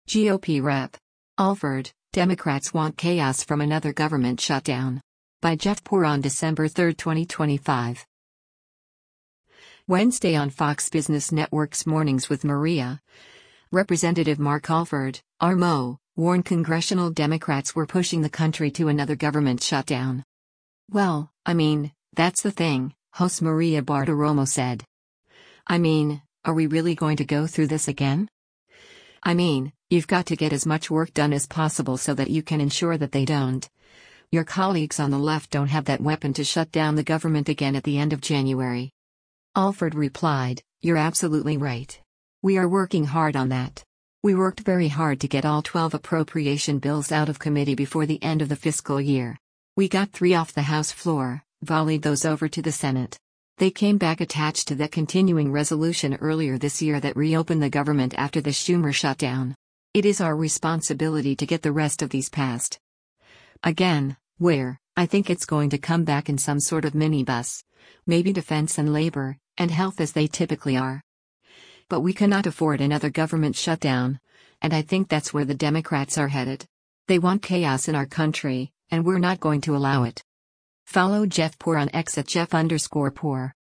Wednesday on Fox Business Network’s “Mornings with Maria,” Rep. Mark Alford (R-MO) warned congressional Democrats were pushing the country to another government shutdown.